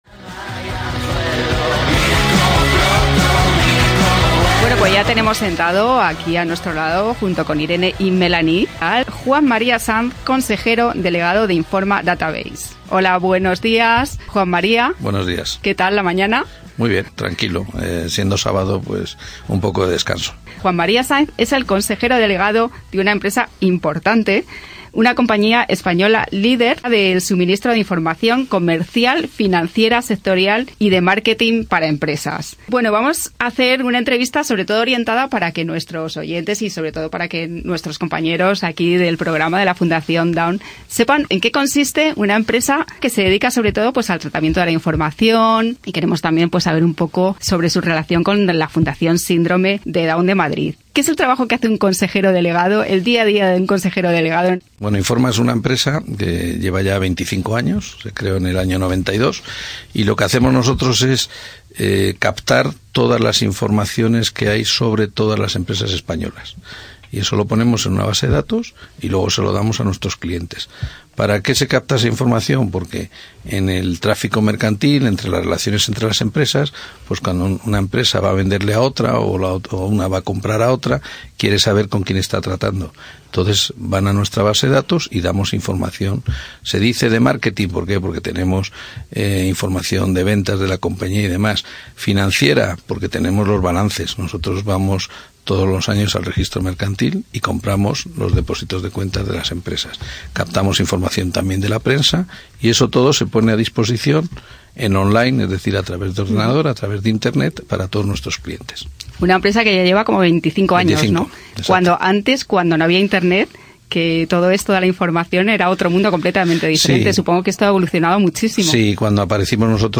¡Ahora nuestras entrevistas en podcast para que los puedas escuchar en cualquier lado!